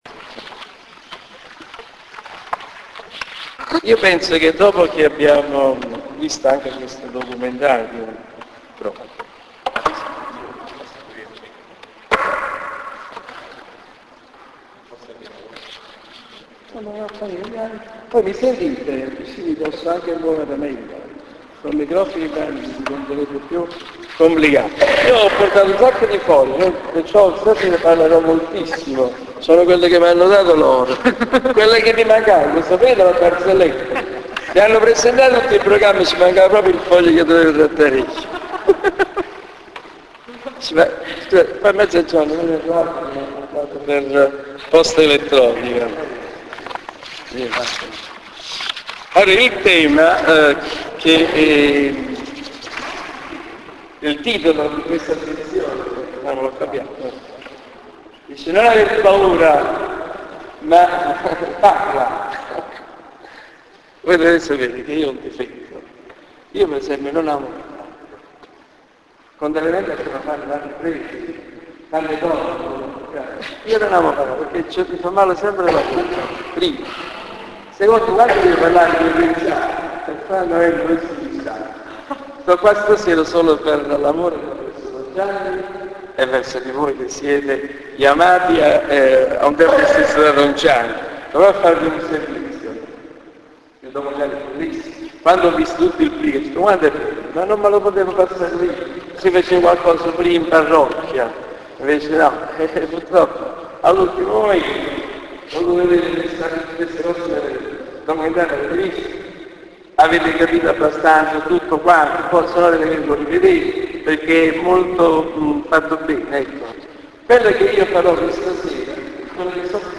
Marted� di San Marcello Il quarto incontro del percorso incentrato su San Paolo, nell'ambito del laboratorio di fede del tempo di Quaresima, si � svolto marted� 24 marzo.